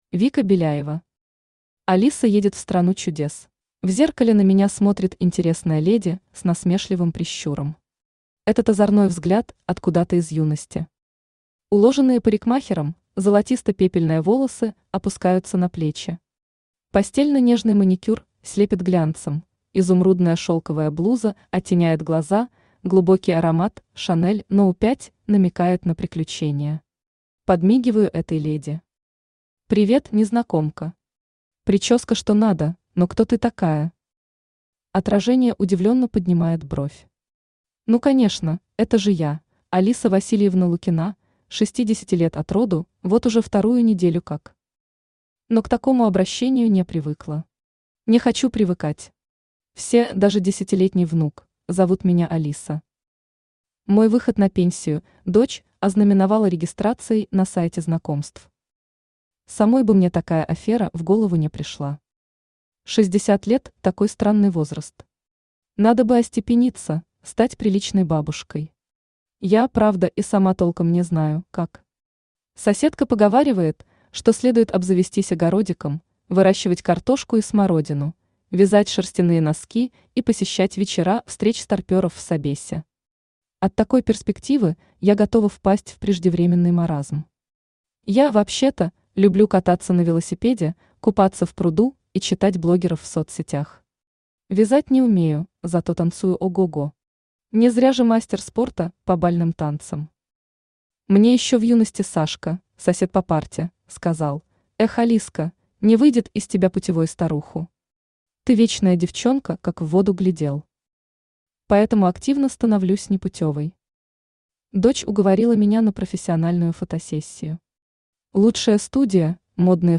Аудиокнига Алиса едет в Страну чудес | Библиотека аудиокниг
Aудиокнига Алиса едет в Страну чудес Автор Вика Беляева Читает аудиокнигу Авточтец ЛитРес.